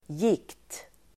Ladda ner uttalet
gikt substantiv, gout Uttal: [jik:t] Böjningar: gikten Definition: en sjukdom med ledvärk (a disease characterized by painful inflammation of the joints) gout substantiv, gikt Förklaring: en sjukdom med ledvärk